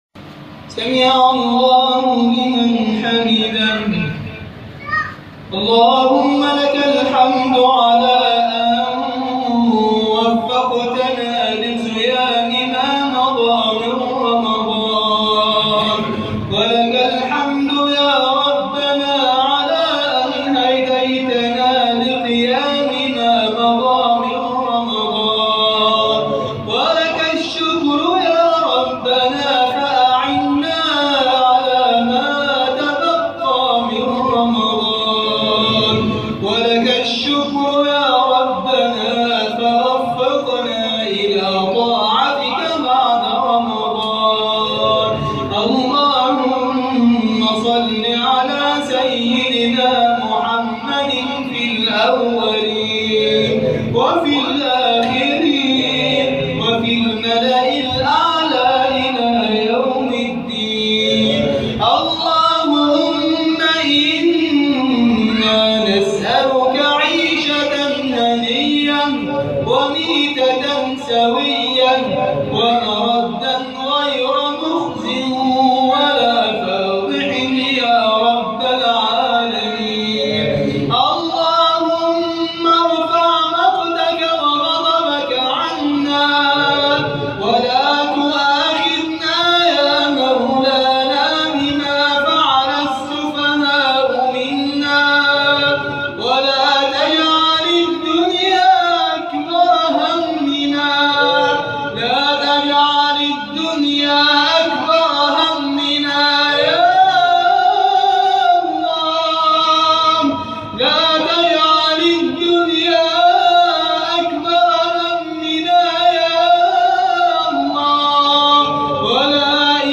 أدعية وأذكار
دعاء خاشع ومؤثر
تسجيل لدعاء خاشع ومميز